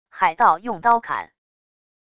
海盗用刀砍wav下载